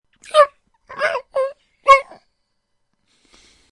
Crying Animal Sound Button - Free Download & Play
Animal Sounds Soundboard190 views